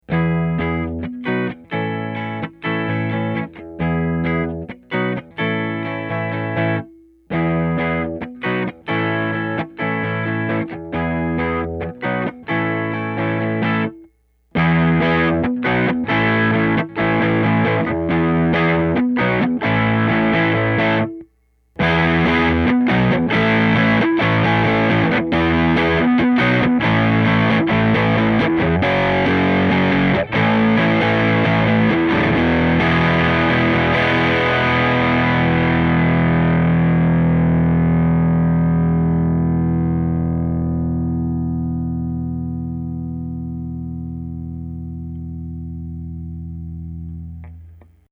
A distortion inspired by vintage Marshall tube amps.
Clip 1: bypassed, 0% Gain, 50%, Gain, 100% Gain
guitar - effect - Ampeg Rocket II amp